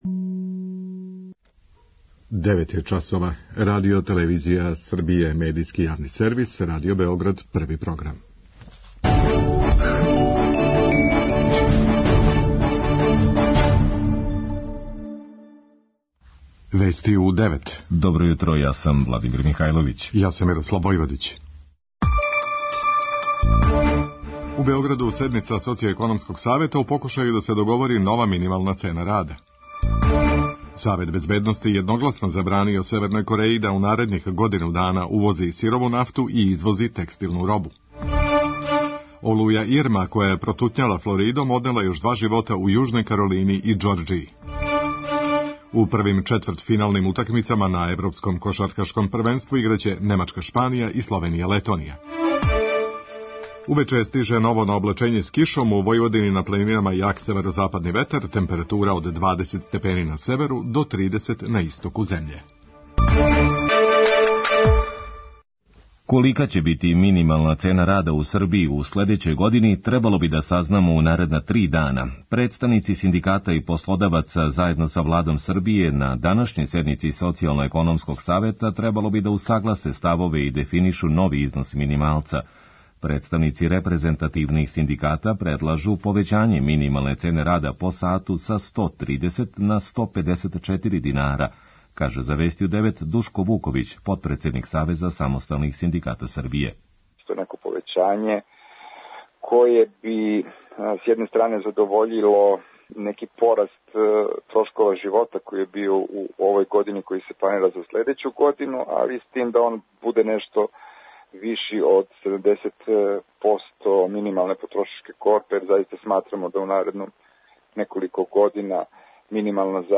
Вести у 9 | Радио Београд 1 | РТС